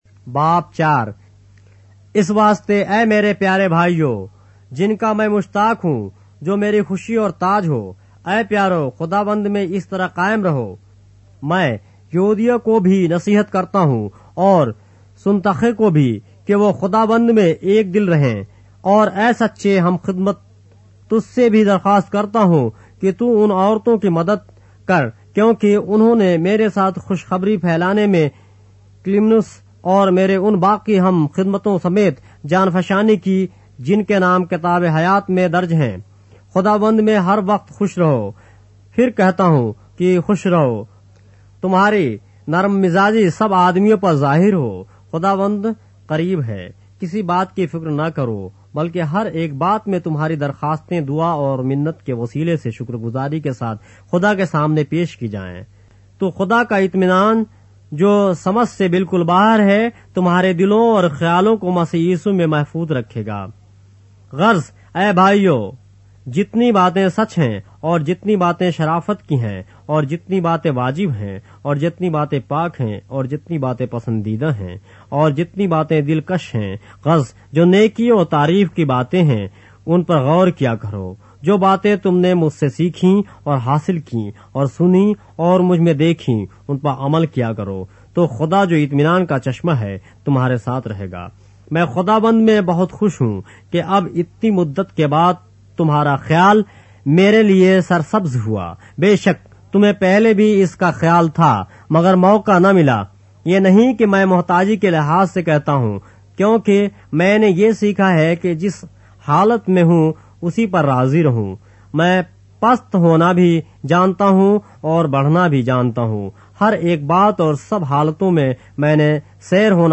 اردو بائبل کے باب - آڈیو روایت کے ساتھ - Philippians, chapter 4 of the Holy Bible in Urdu